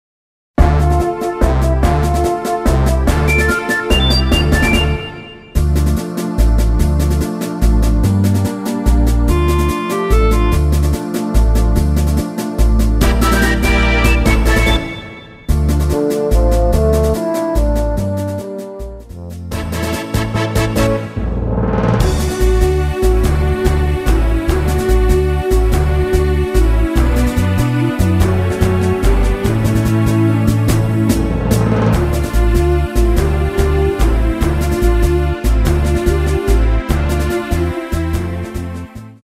MR입니다. 키 Dm 가수